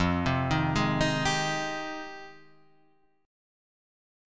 Fm9 Chord